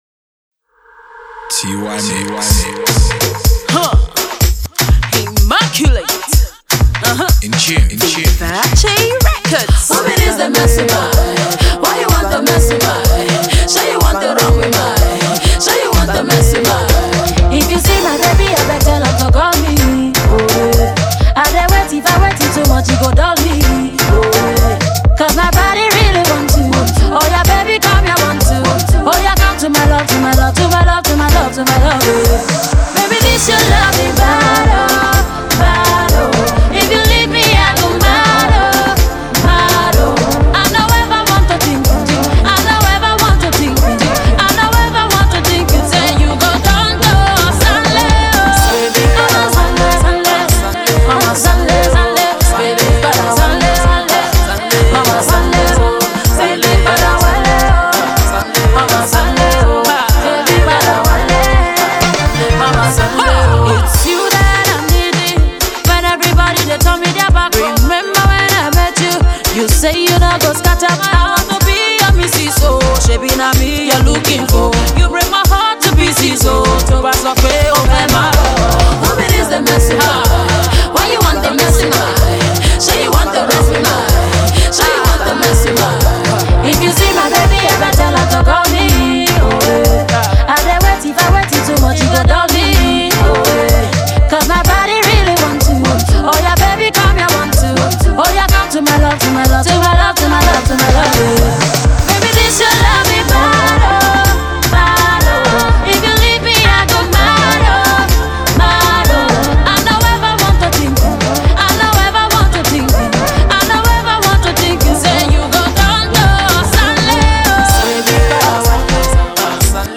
racy afro-pop